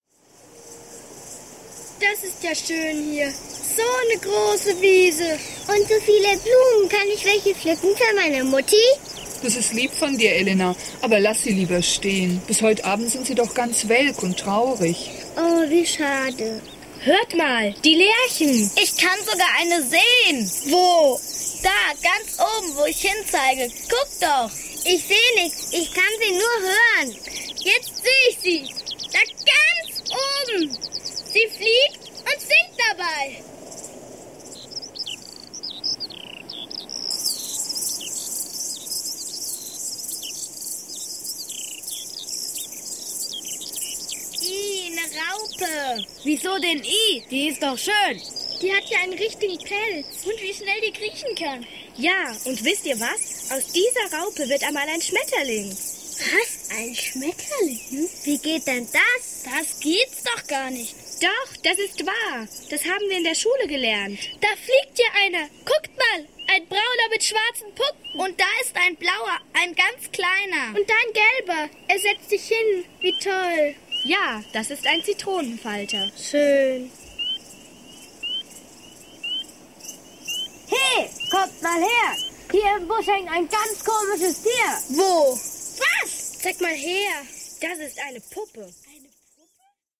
Hörszene 2